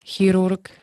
Zvukové nahrávky niektorých slov